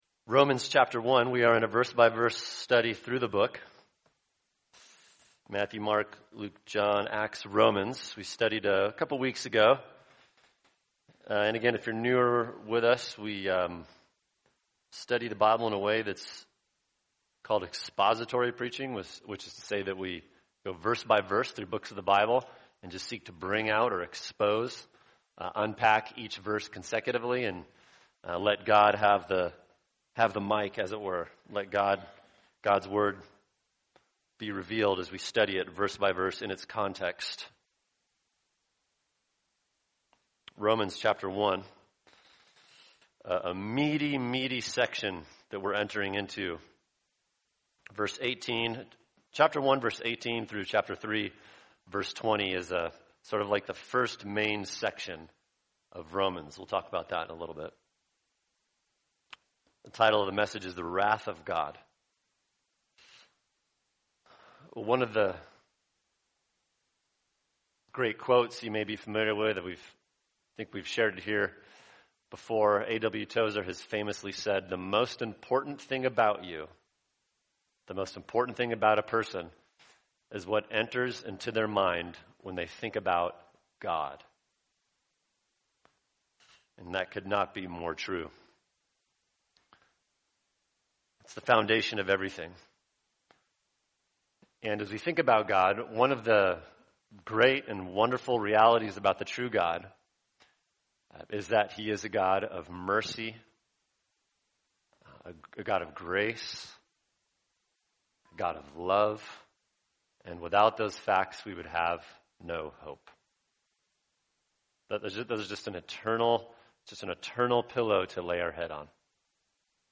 [sermon] Romans 1:18 The Wrath of God | Cornerstone Church - Jackson Hole